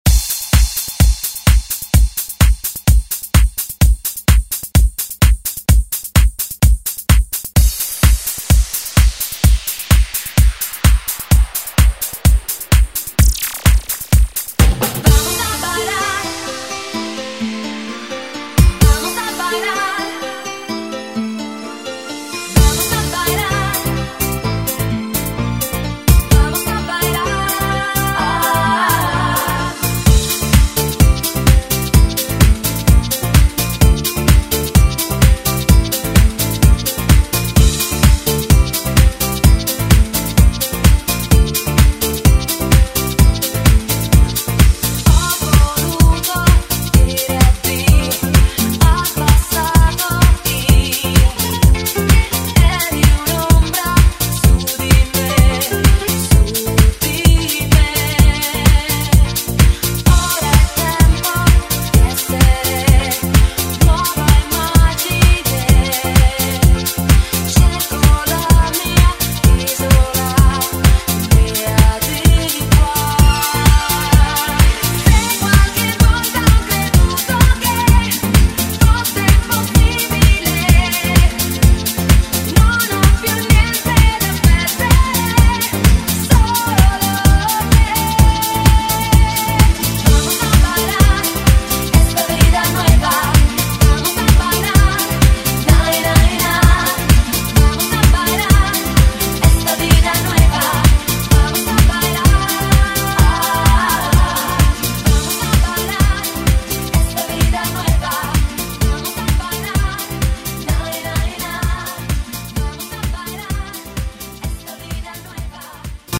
Genres: 2000's , DANCE , RE-DRUM
Clean BPM: 124 Time